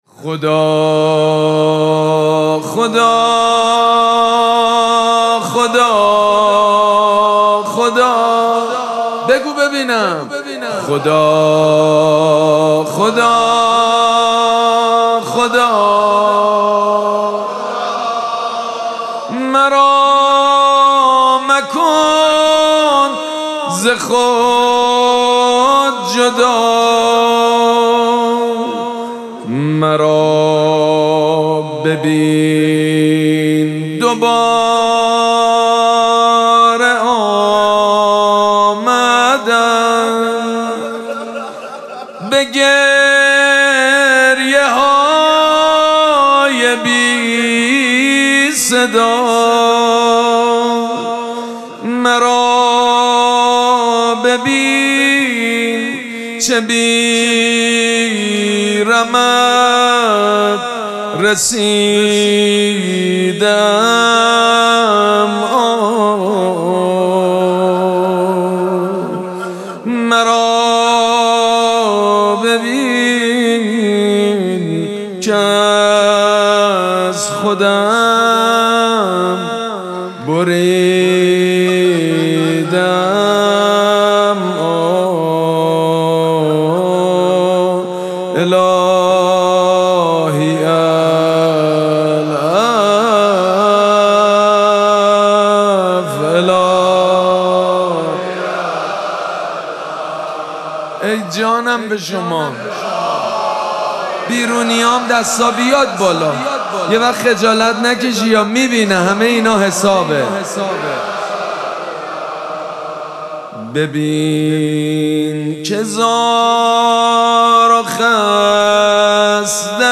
مراسم مناجات شب بیست و یکم ماه مبارک رمضان
مناجات
حاج سید مجید بنی فاطمه